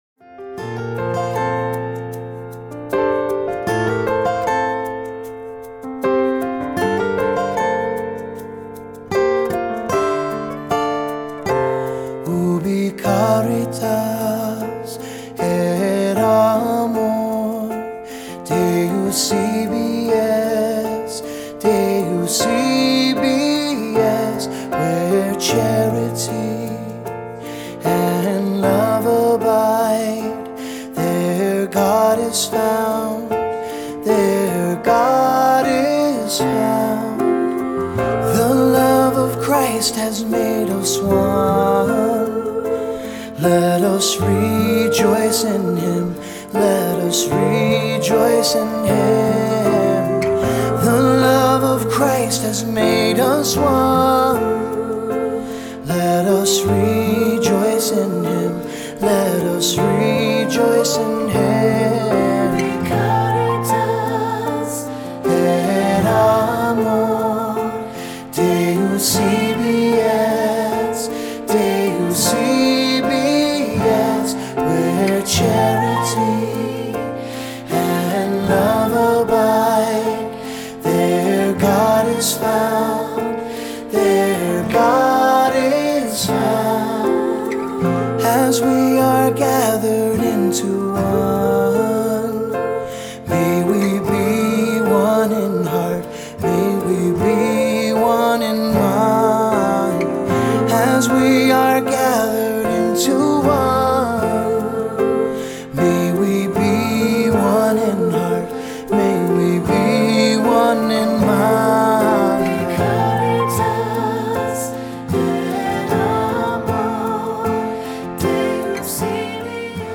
Voicing: Two-part choir; cantor; assembly